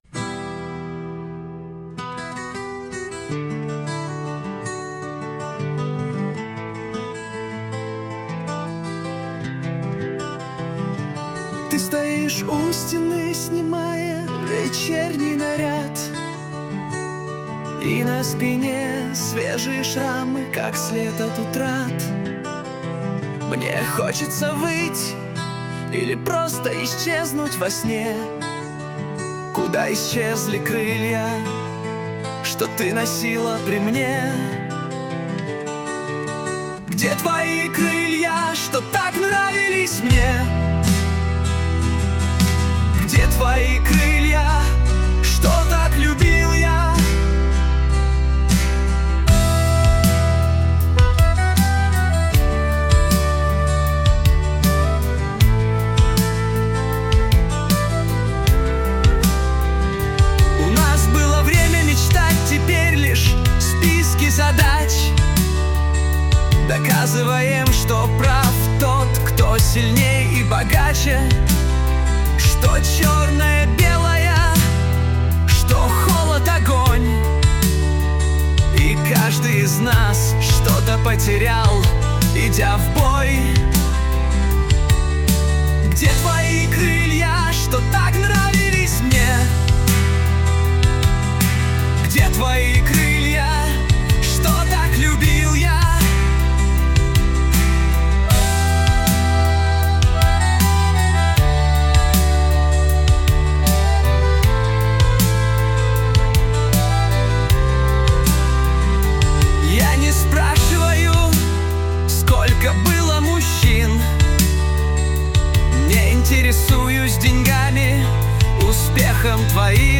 RUS, Romantic, Lyric, Rock, Indie | 03.04.2025 20:51